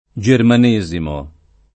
germanesimo [ J erman %@ imo ]